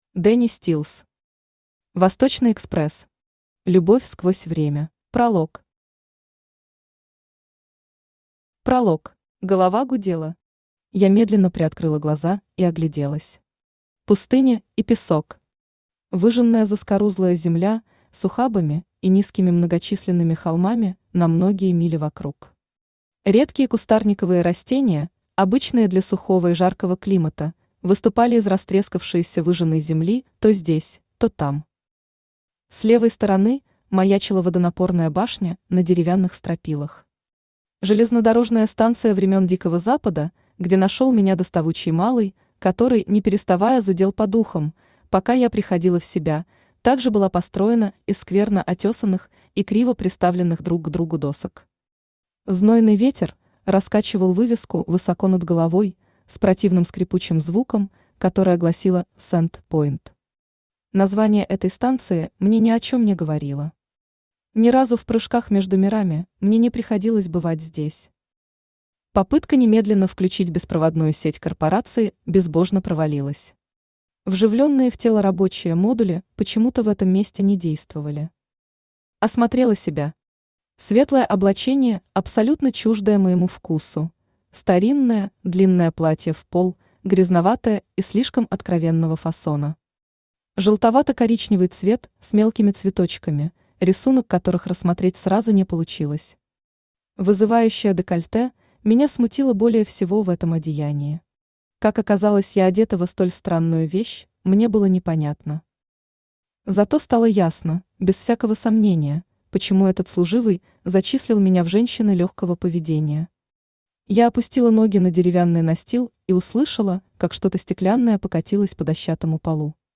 Аудиокнига Любовь сквозь время | Библиотека аудиокниг
Aудиокнига Любовь сквозь время Автор Дэнни Стилс Читает аудиокнигу Авточтец ЛитРес.